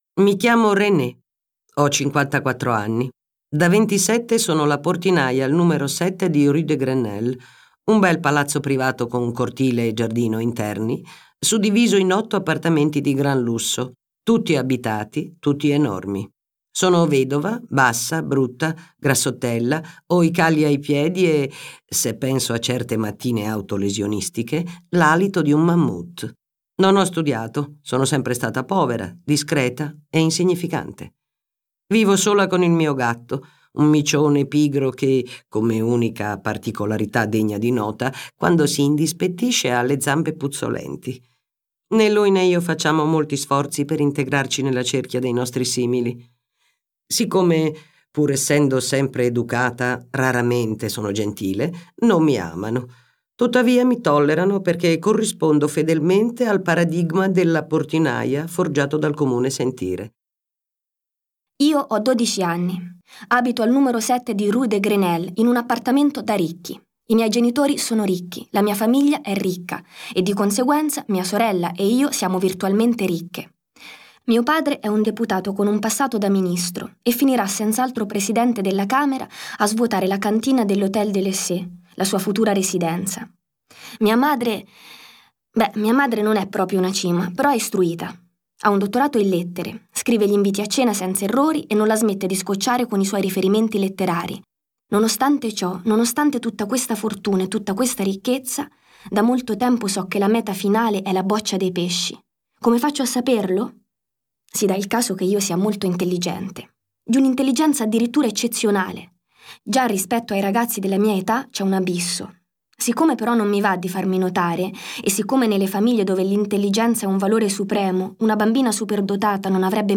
letto da Anna Bonaiuto, Alba Rohrwacher